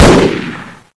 hksShoot3.ogg